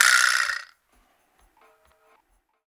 129-FX2.wav